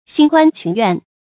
兴观群怨 xìng guān qún yuàn 成语解释 兴：联想；观：观察；群：合群；怨：怨恨。古人认为读《诗经》可以培养人的四种能力。